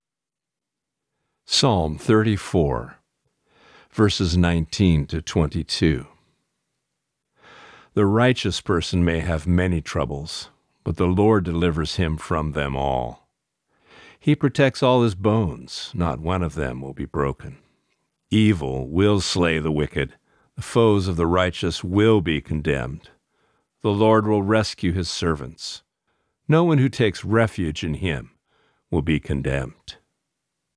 Reading: Psalm 60 (NIV)*